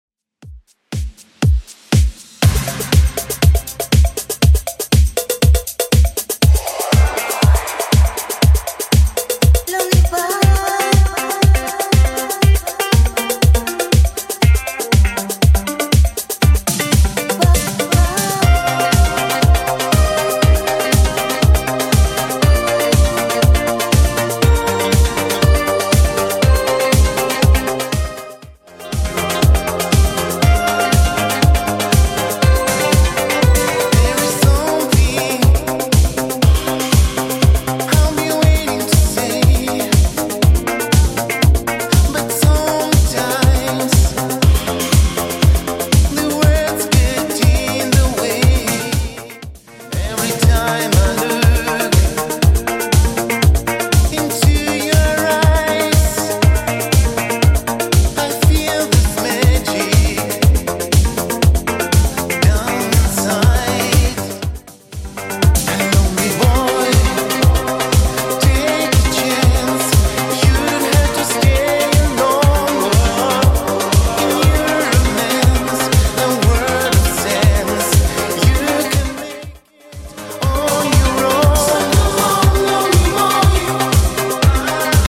Genre: REGGAETON
BPM: 117